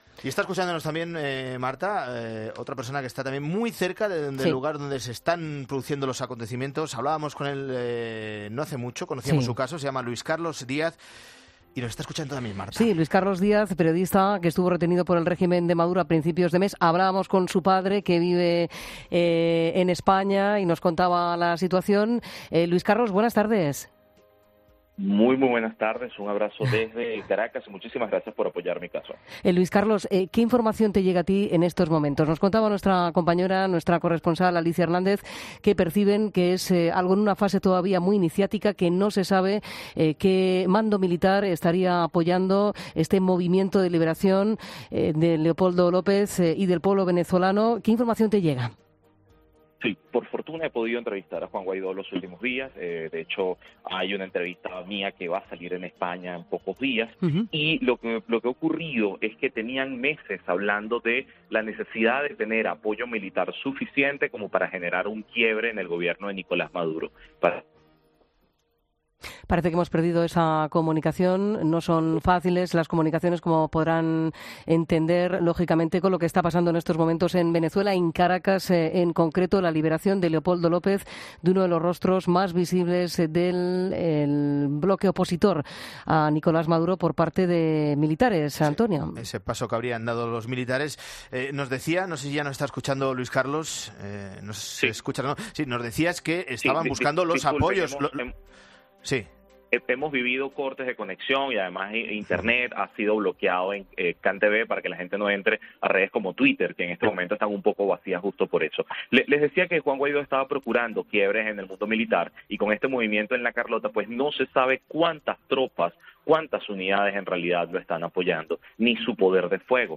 periodista venezolano
como demuestra que el régimen haya cortado las conexiones a Internet y se interrumpan constantemente las comunicaciones